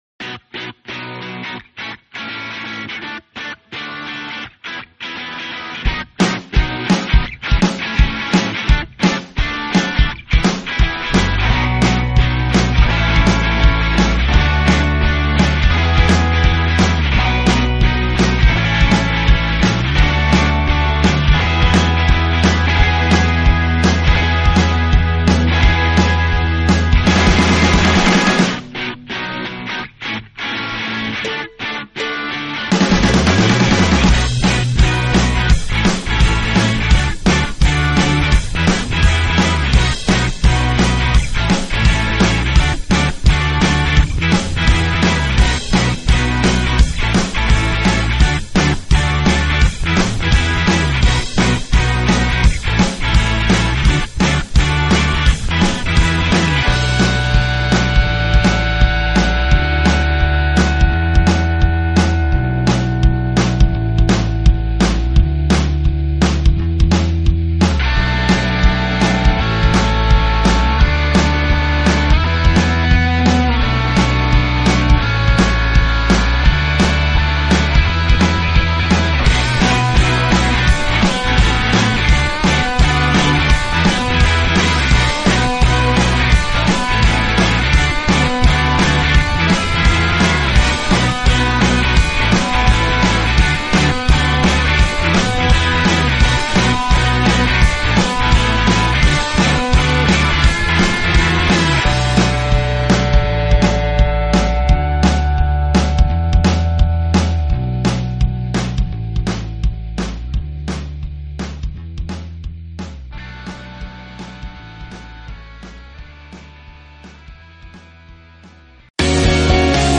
Rozmowy w Nocy to internetowy talk-show na żywo z udziałem słuchaczy w środy 23.00. Jest to audycja o życiu ludziach i ich problemach.